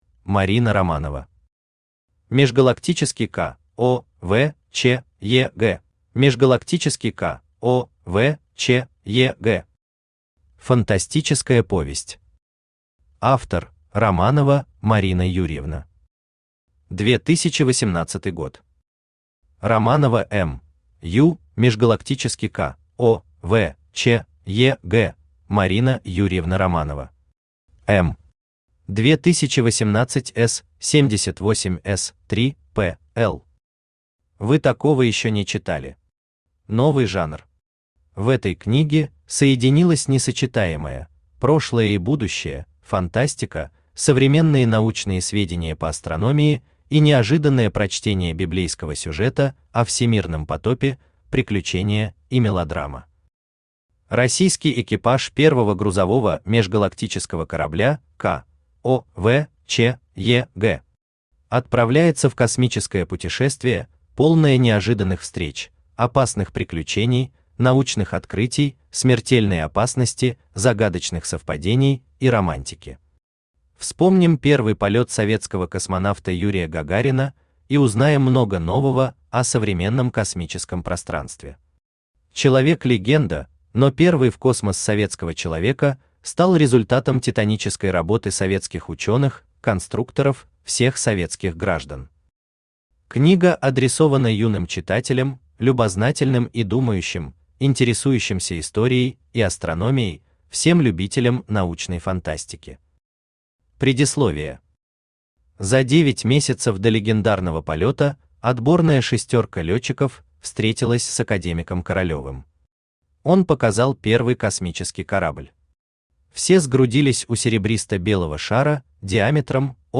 Автор Марина Юрьевна Романова Читает аудиокнигу Авточтец ЛитРес.